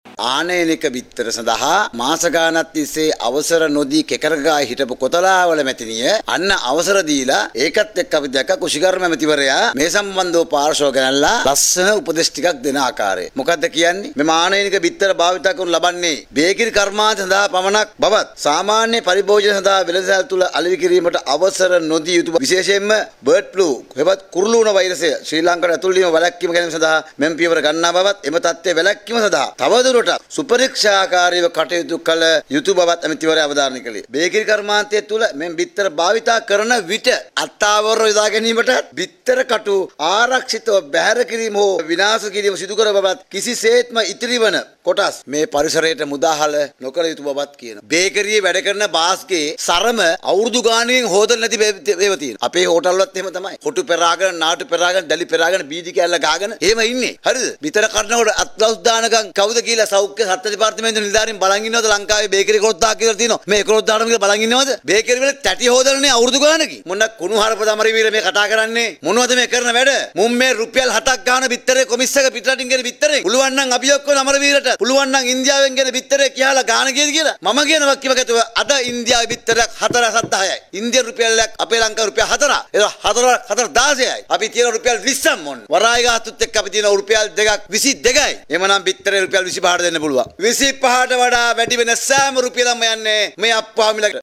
මේ සම්බන්ධයෙන් අද පැවති මාධ්‍ය හමුවකදී